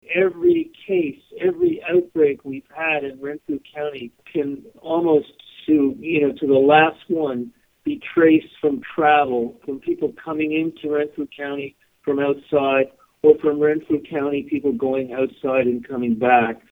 Acting Medical Officer of Health with the Renfrew County and District Health Unit, Doctor Robert Cushman, says all outbreaks in our area can be traced to travel.